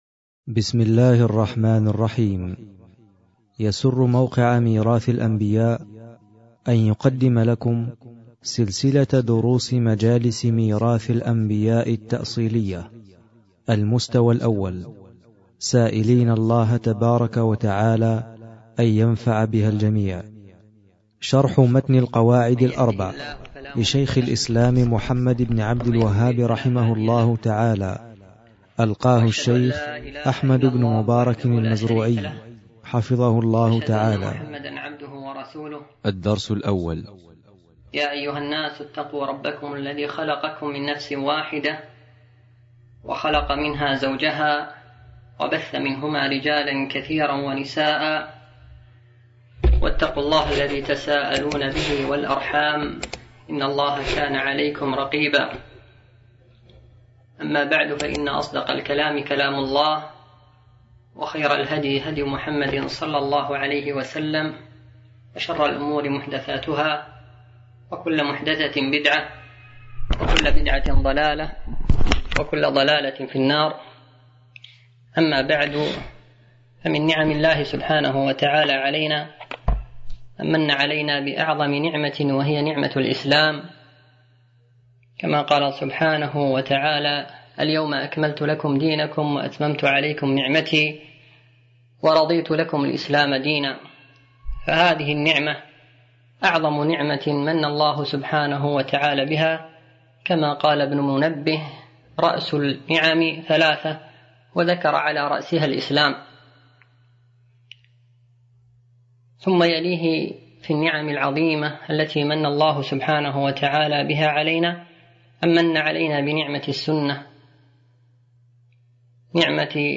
شرح القواعد الأربع - الدرس الأول
التنسيق: MP3 Mono 22kHz 32Kbps (CBR)